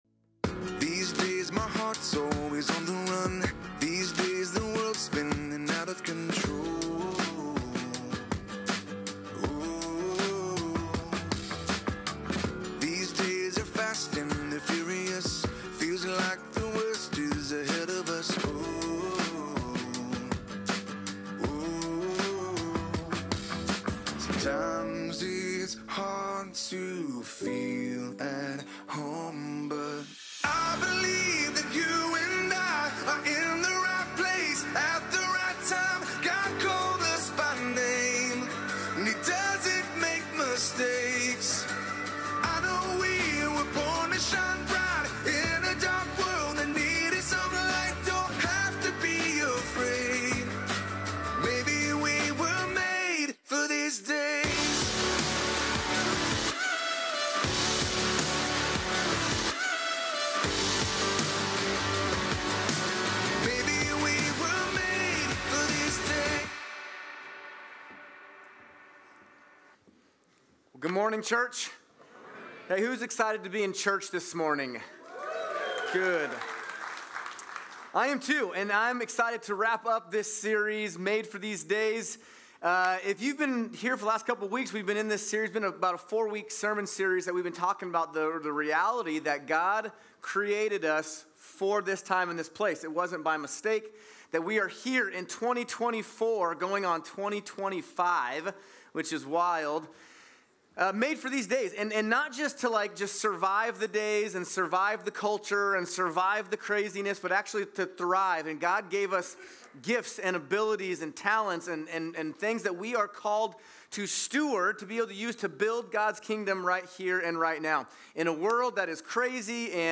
Made For These Days - Week Four // Steward Our Health // Hayden Campus
Sermon